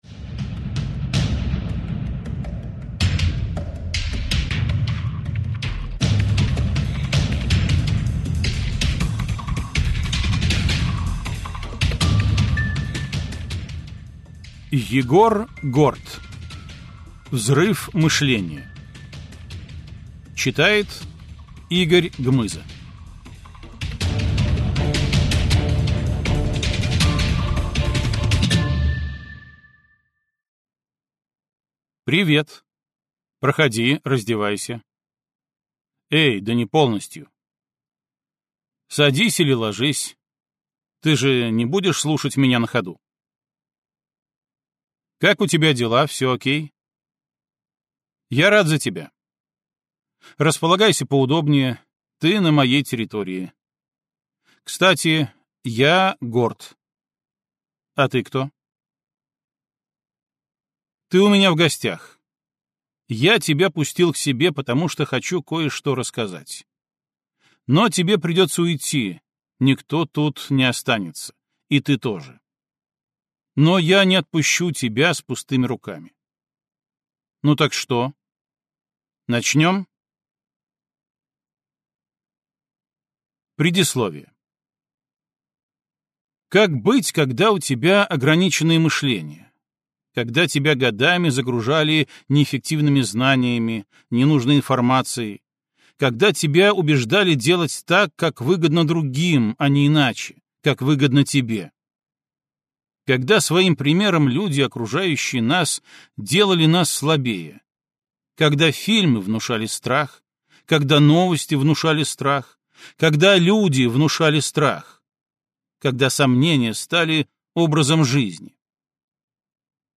Аудиокнига Взрыв мышления | Библиотека аудиокниг